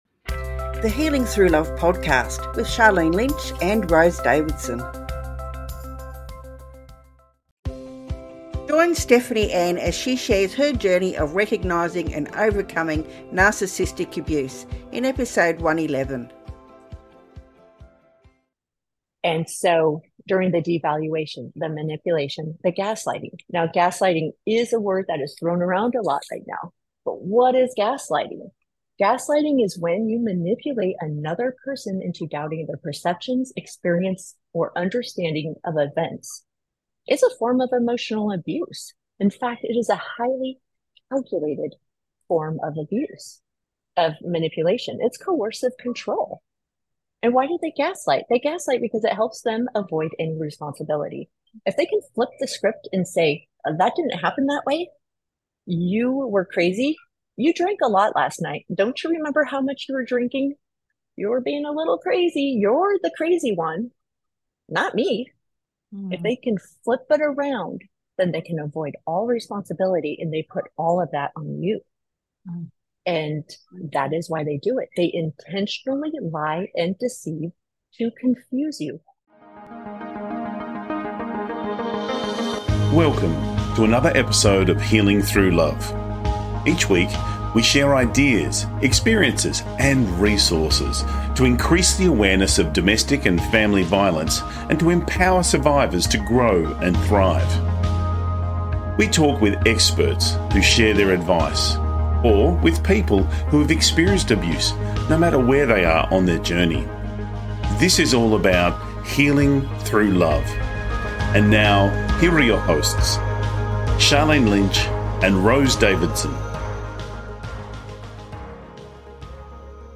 Key Points from the Interview: How to recognize the signs of narcissistic behaviour and the cycle of abuse. Practical steps for safely leaving a toxic relationship and breaking the trauma bond. The role of Emotional Freedom Technique (EFT) and mindset shifts in healing and reclaiming self-worth.